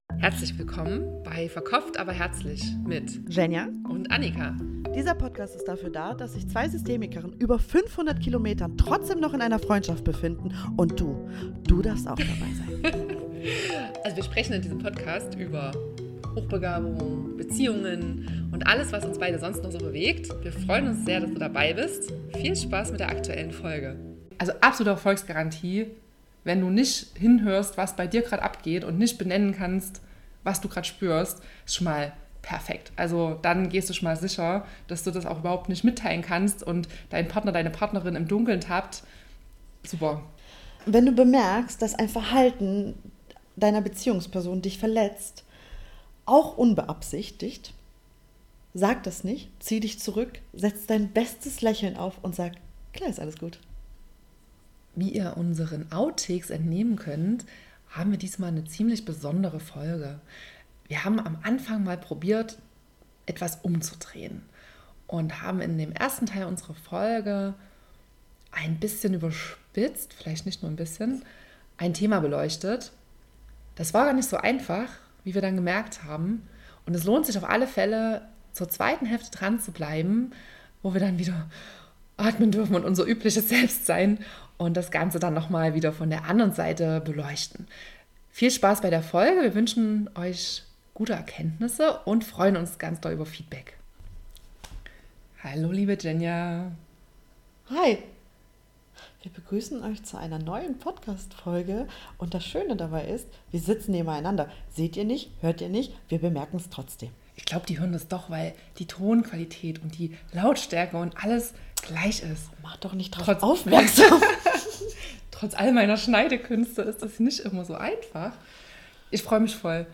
Es war gar nicht so einfach, bis Minute 26 den Sarkasmus aufrecht zu erhalten.
Es lohnt sich jedenfalls, dranzubleiben, wenn wir ab Minute 26:40 die Ironie beiseitelegen und diesmal ernst gemeinte praktische Tipps zusammentragen. Gewinnspiel Wer uns als erstes die richtige Anzahl an gehörten „Miaus“ in dieser Folge per dm schickt, gewinnt einen Sticker!